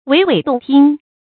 wěi wěi dòng tīng
娓娓动听发音